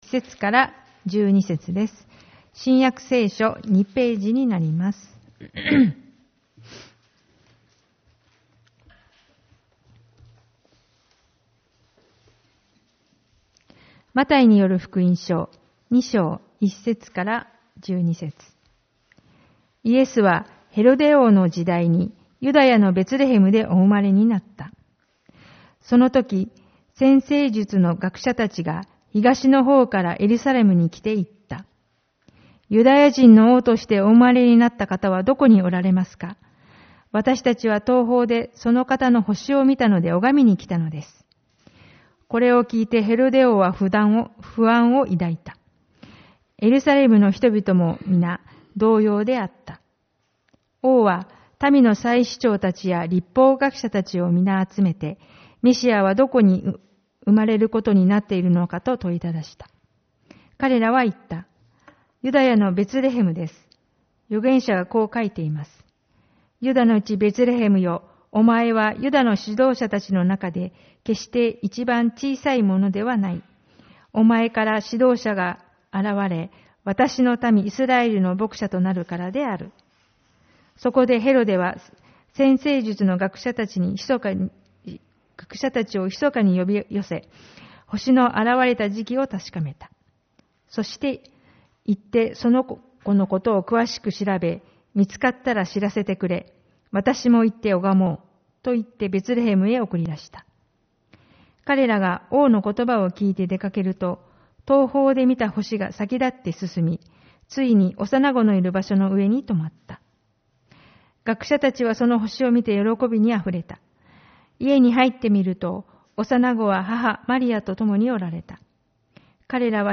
主日礼拝 「星に導かれて」 マタイによる福音書2:1-12